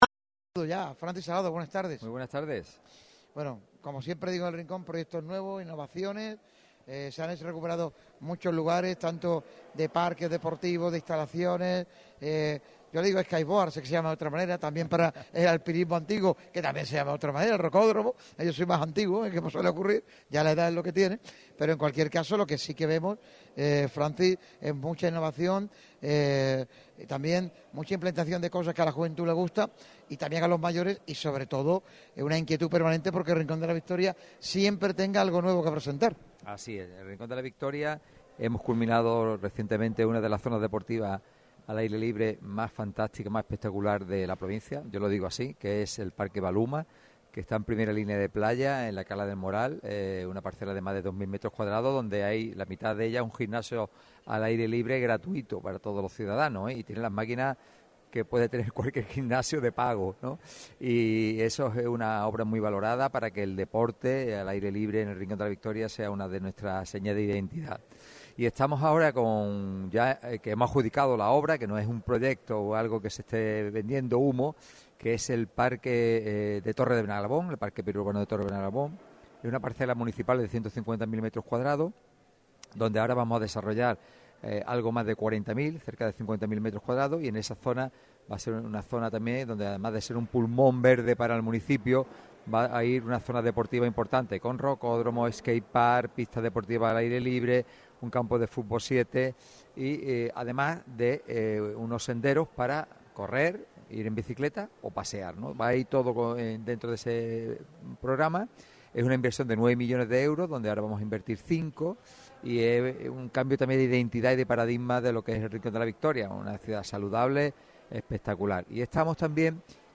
El alcalde de Rincón de la Victoria y presidente de la Diputación Provincial de Málaga, Francisco Salado, ha pasado por los micrófonos de la radio del deporte este martes en el programa especial dedicado al deporte de la localidad.